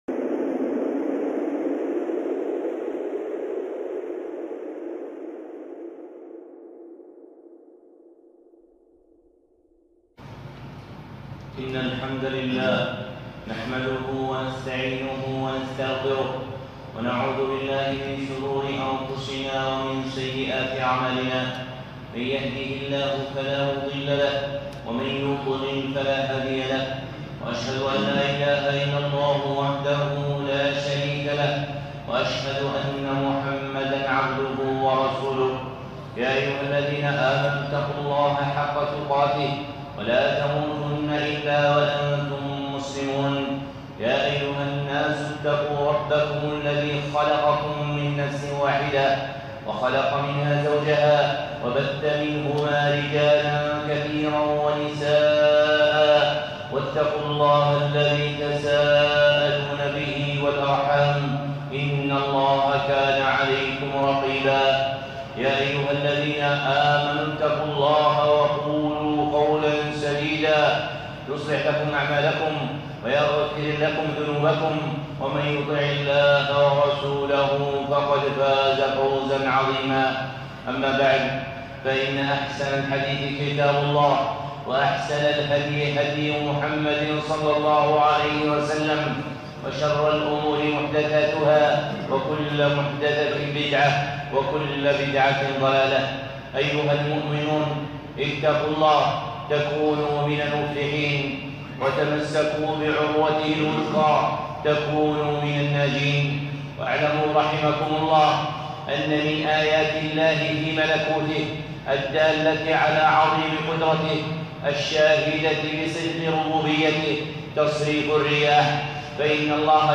خطبة (الرياح… بشرى أم عذاب